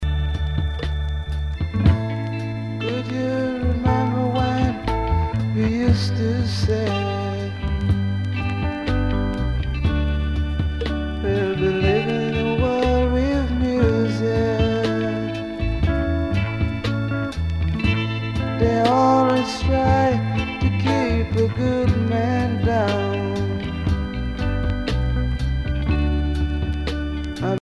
danse : slow
Pièce musicale éditée